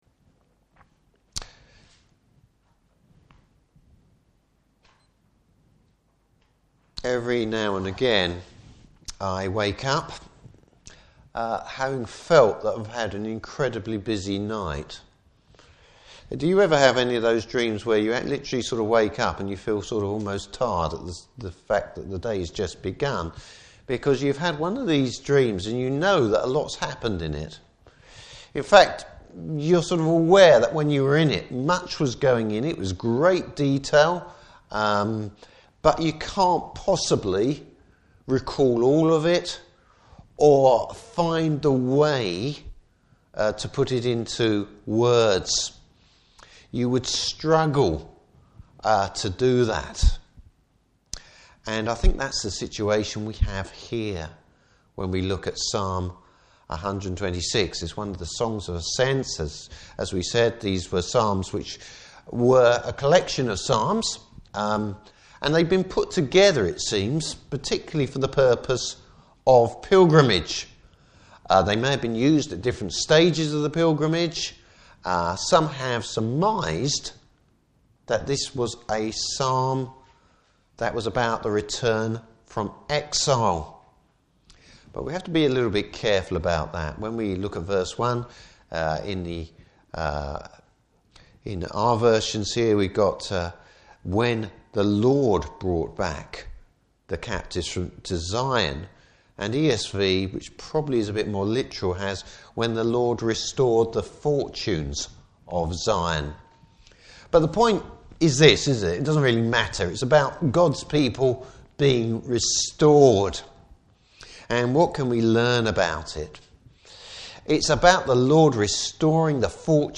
New Years Sermon: Restore our Fortunes O Lord!
Service Type: Evening Service Bible Text: Psalm 126.